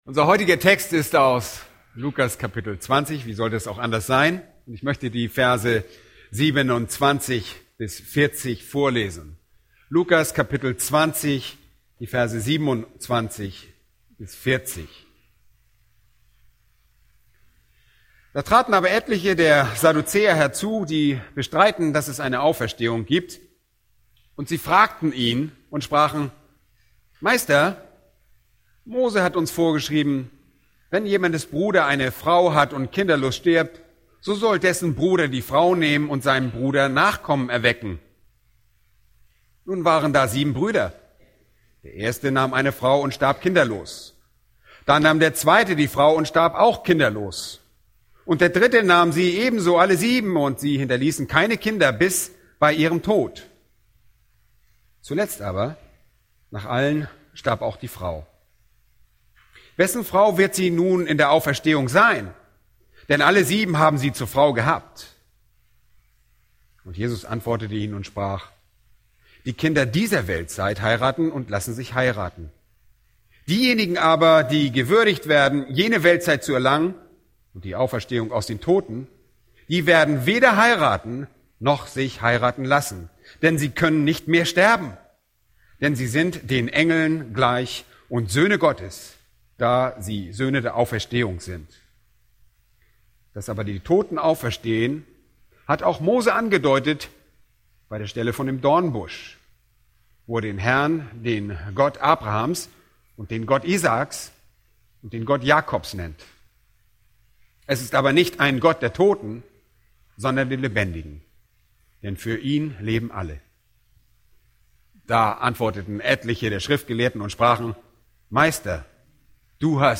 Bibelstunden Startseite Predigt-Archiv Bibelstunden